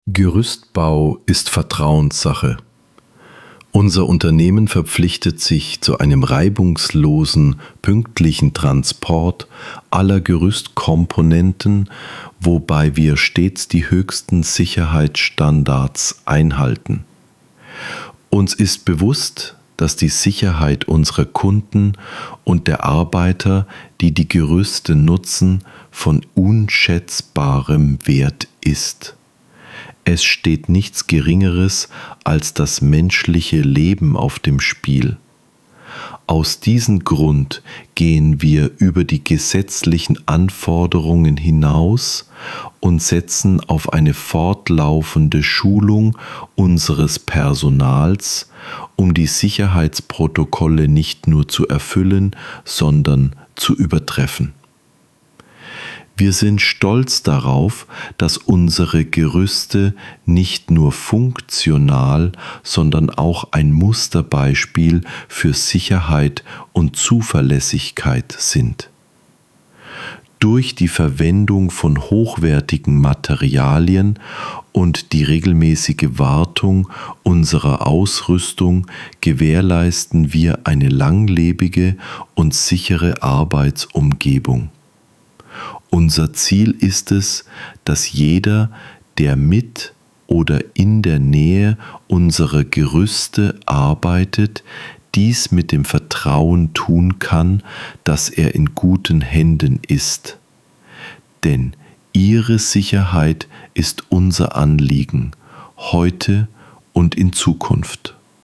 Text vorlesen – Sicherheit & Vertrauen bei Gerüstbau Arens